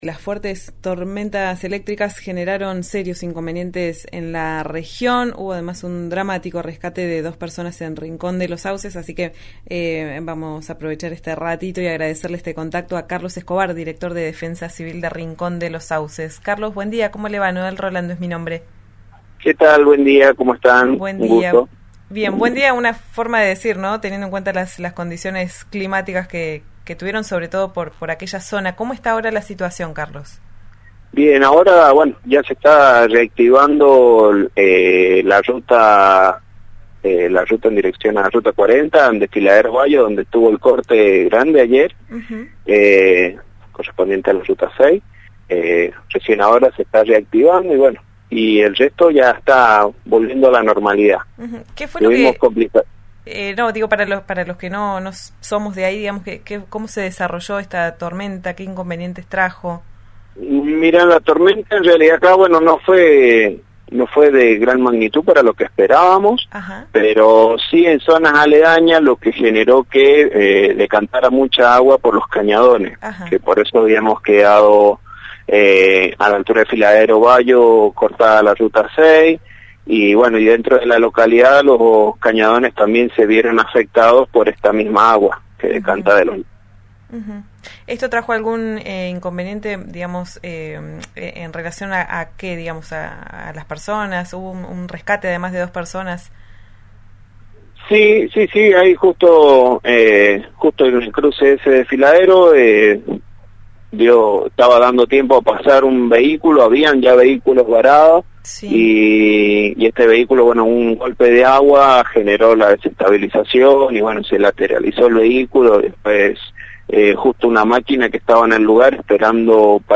Escuchá a Carlos Escobar, director de Defensa Civil de Rincón de los Sauces, en Ya Es Tiempo por RN Radio